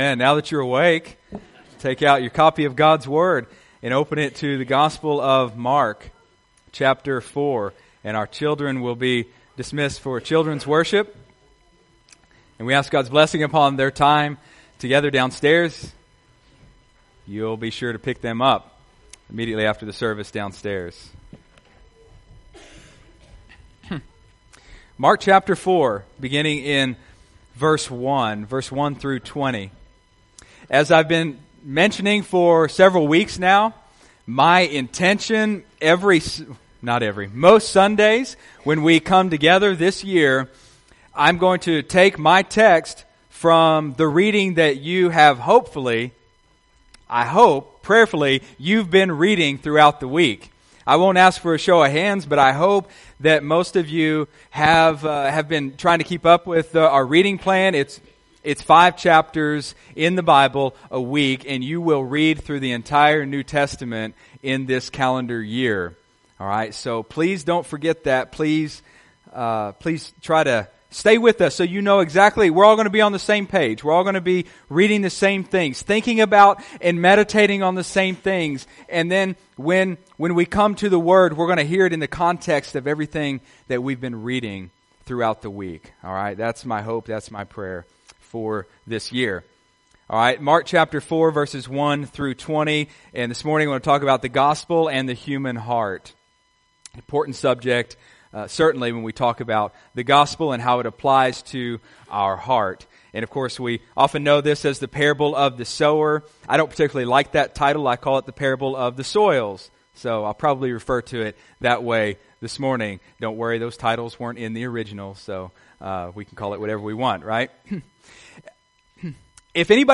Sunday, January 8, 2017 (Sunday Morning Service)